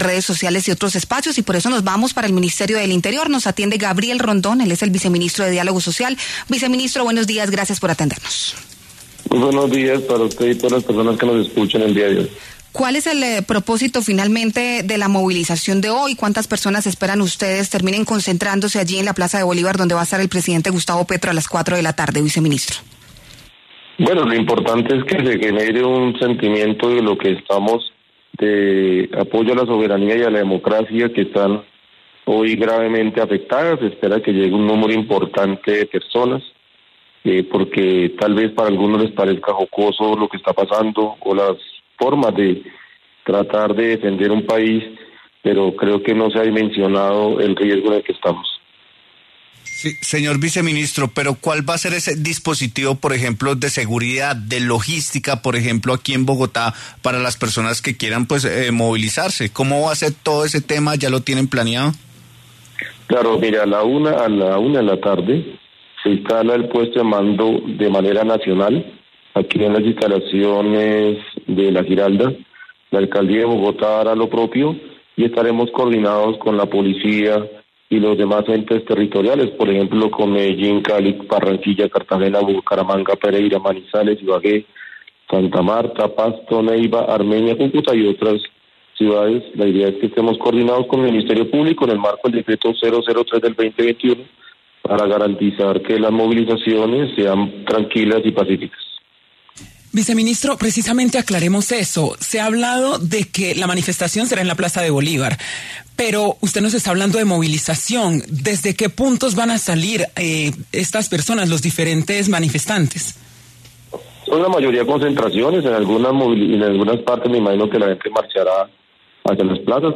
Durante la entrevista con la W, Rondón insistió en que el sentido político de las marchas es colectivo y nacional.